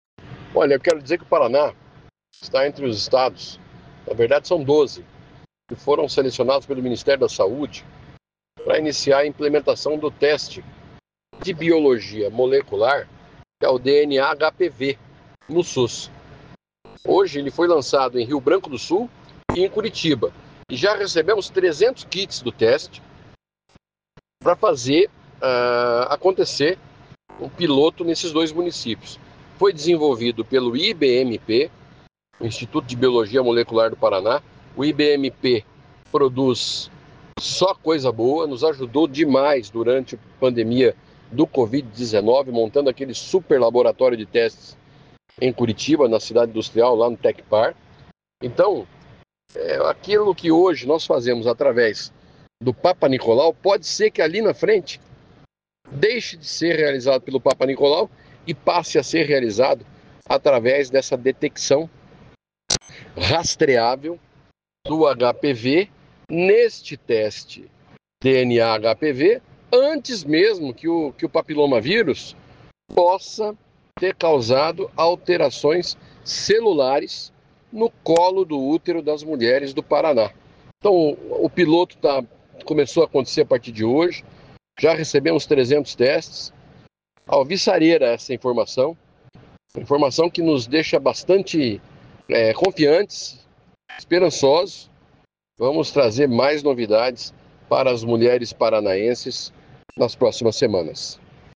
Sonora do secretário da Saúde, Beto Preto, sobre a implementação de teste inovador para rastrear câncer do colo do útero